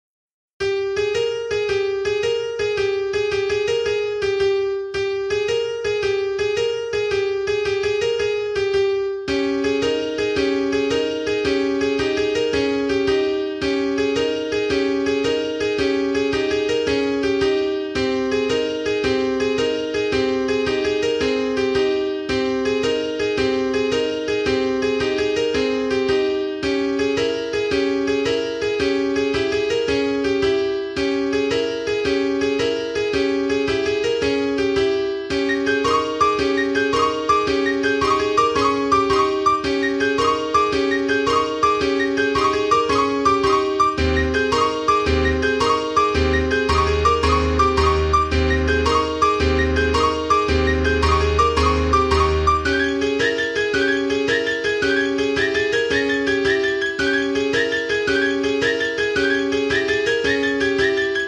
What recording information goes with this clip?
Its performed with a midi keyboard